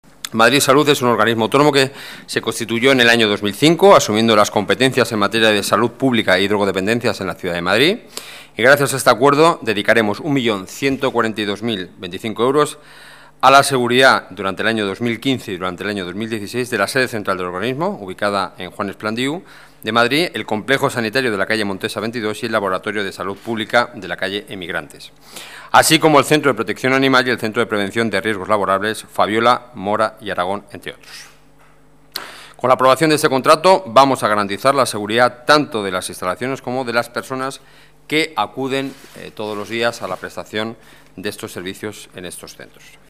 Nueva ventana:Declaraciones del portavoz del Gobierno municipal, Enrique Núñez: Seguridad Centros de Salud